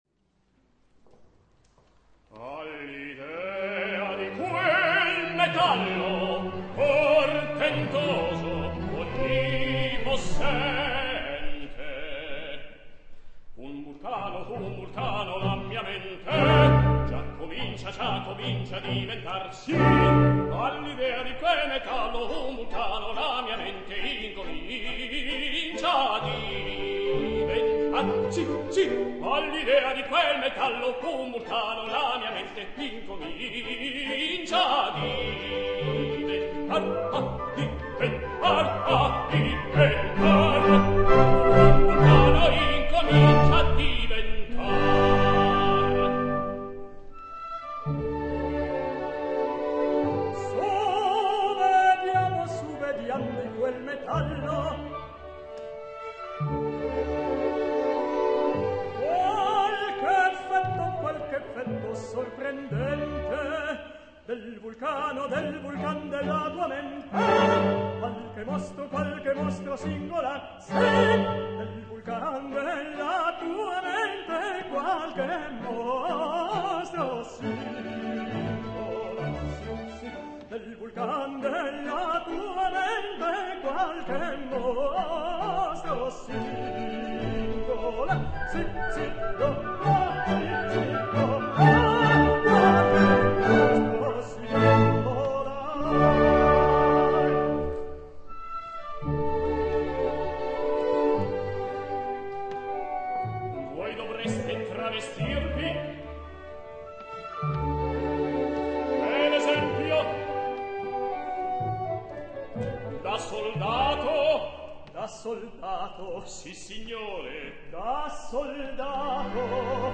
Orchestra del Festival di Torrechiara
baritono
tenore